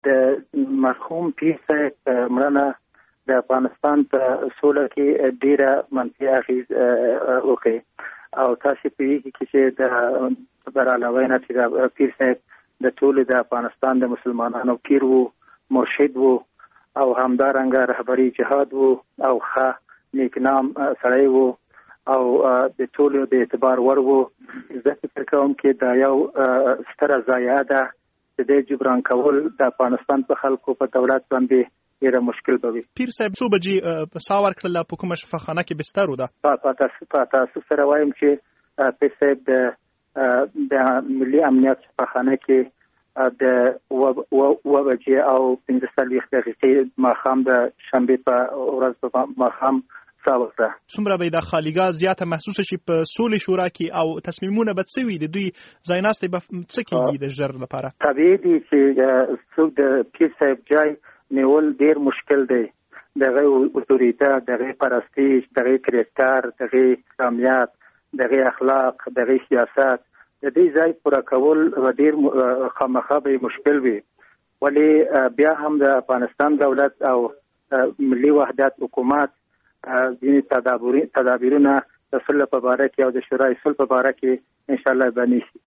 د سولې شورا له مرستیال مولوي عبدالخبیر اوچقون سره مرکه